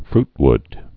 (frtwd)